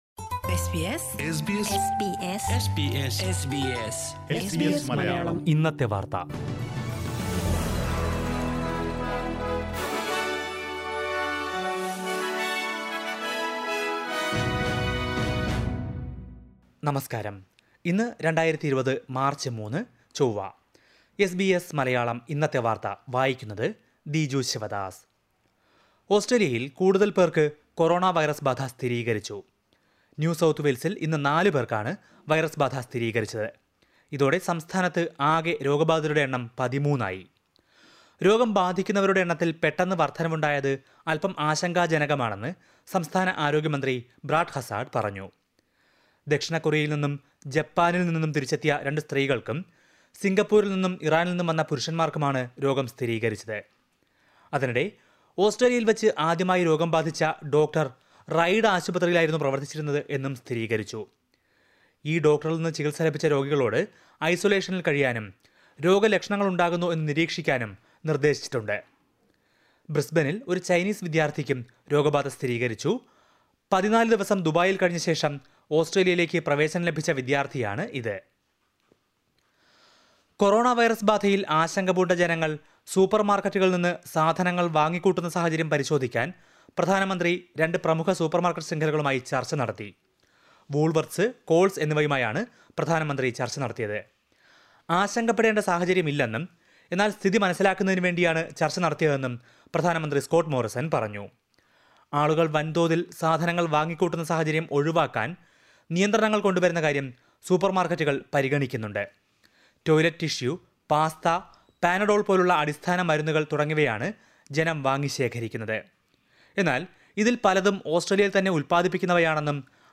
SBS Malayalam Today's News: March 3, 2020